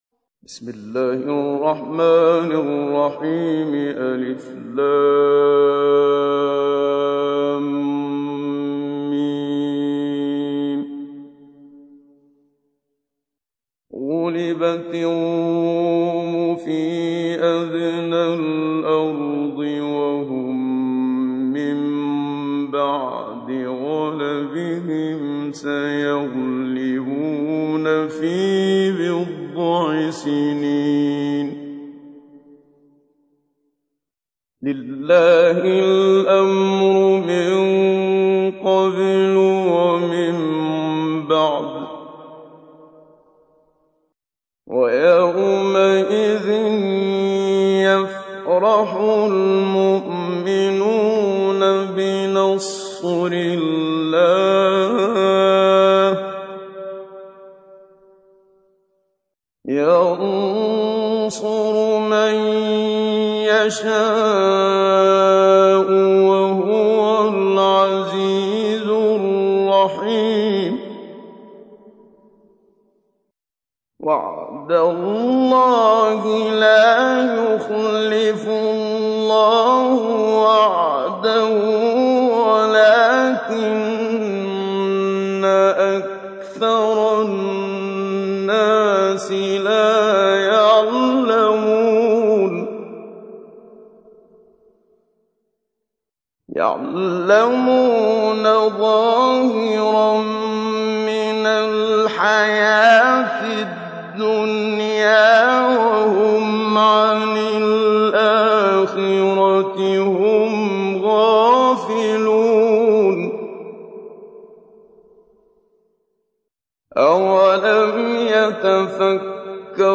تحميل : 30. سورة الروم / القارئ محمد صديق المنشاوي / القرآن الكريم / موقع يا حسين